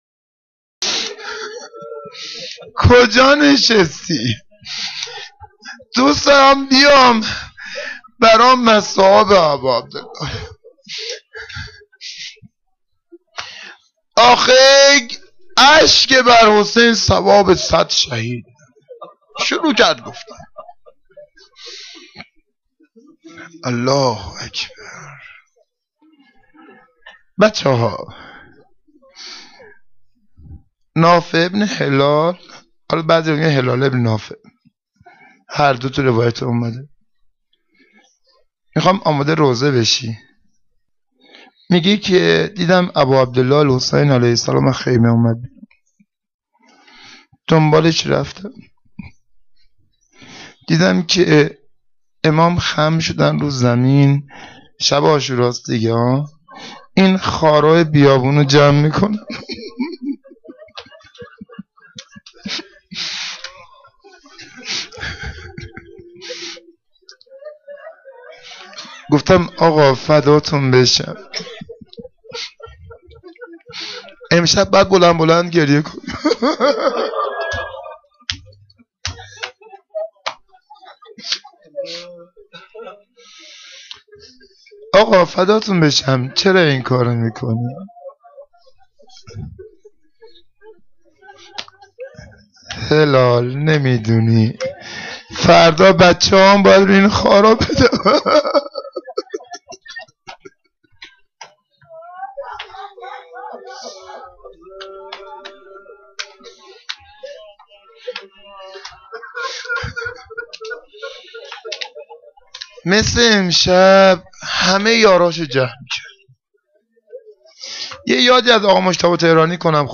سخنرانی10.3.wma